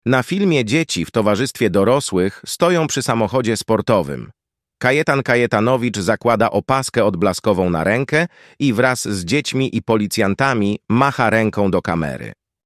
Nagranie audio Audiodeskrypcja filmu.